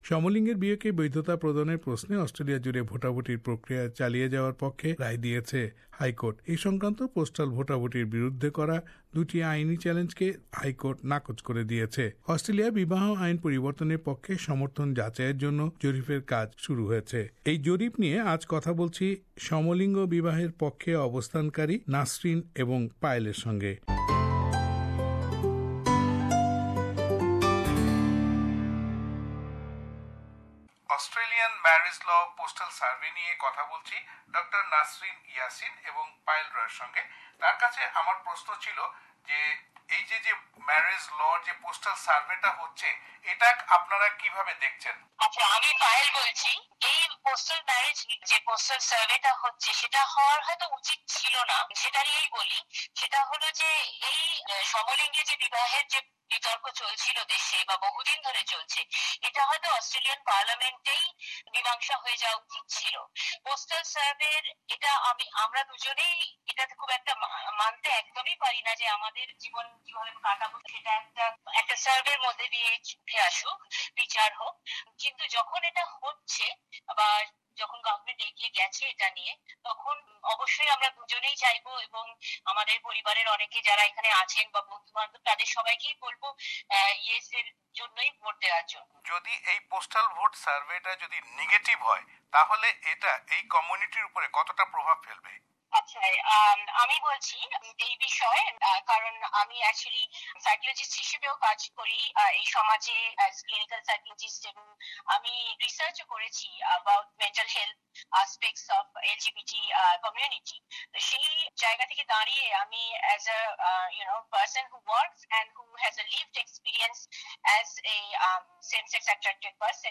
Same-sex marriage debate : Interview